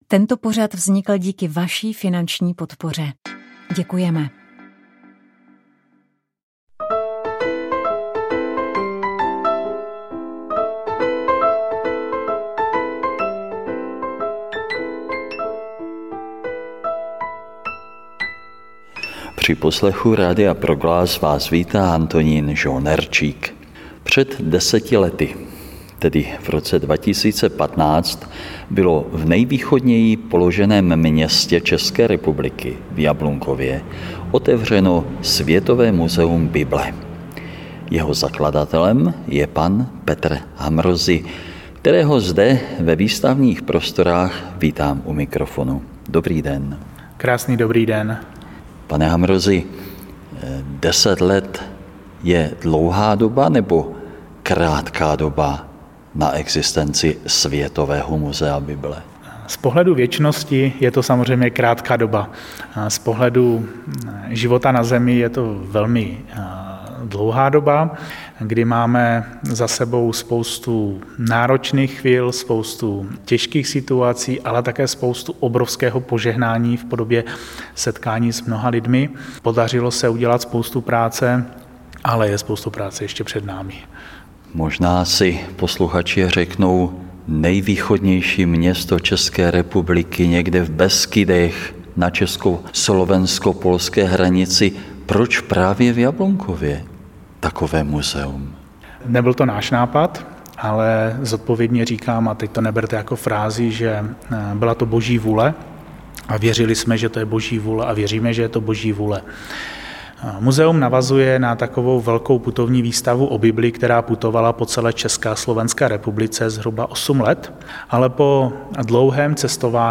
v rozhovoru